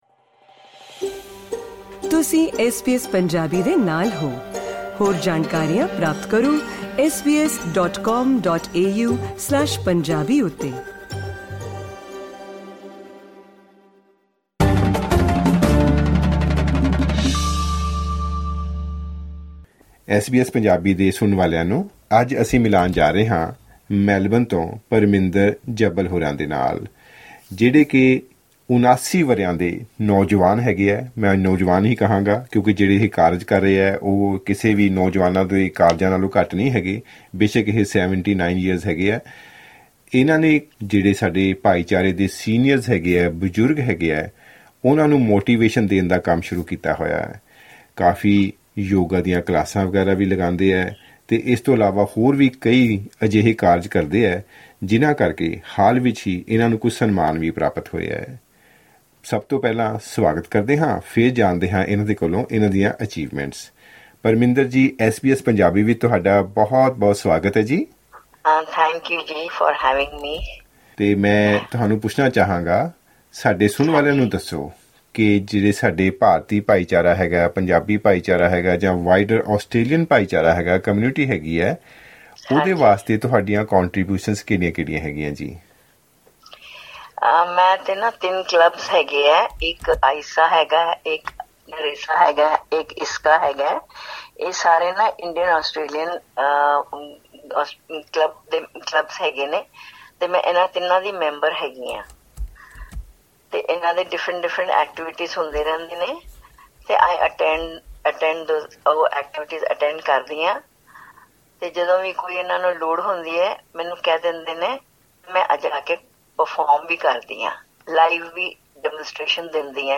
ਗਲ ਬਾਤ